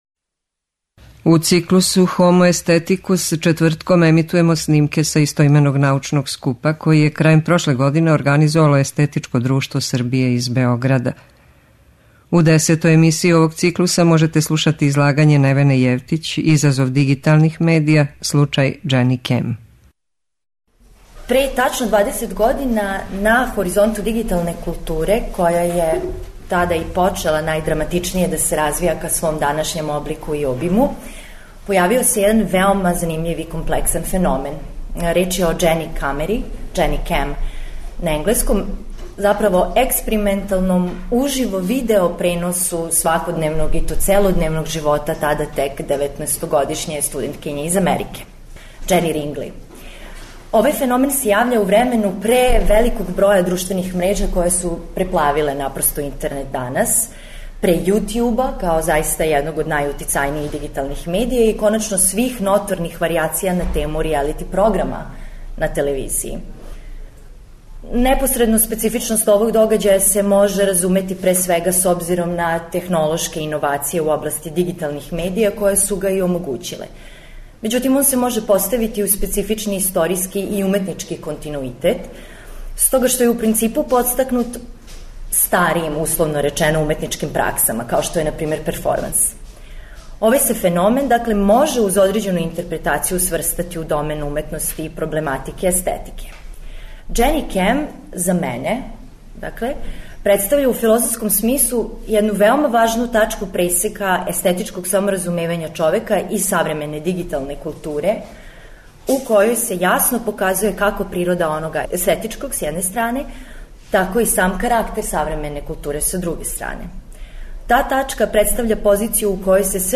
Научни скупoви